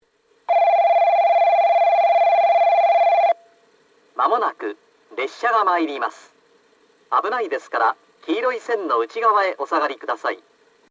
☆旧放送
1番のりば接近放送　男声 以前の放送は九州カンノ型Aでした。音量が小さい上に、2番のりばの放送は入るタイミングが遅く、2回目の放送では列車の進入と重なります。
スピーカーはＴＯＡラッパ（灰色）でした。